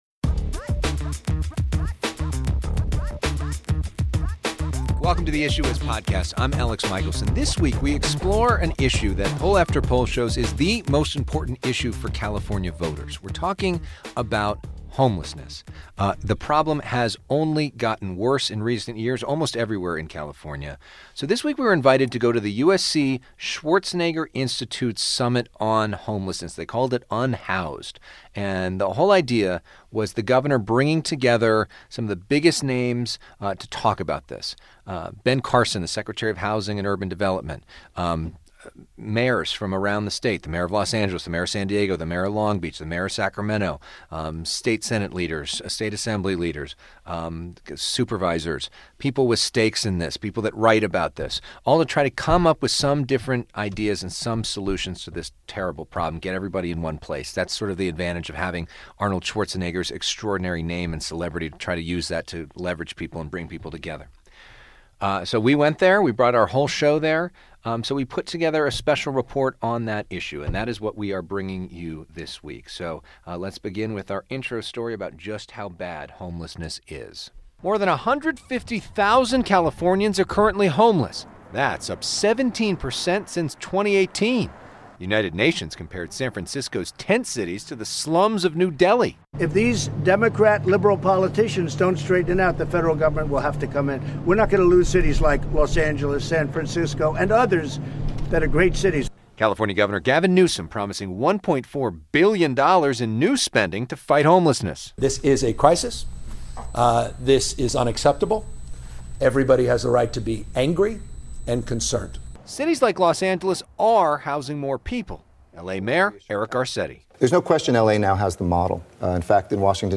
broadcast from FOX 11 Studios in Los Angeles.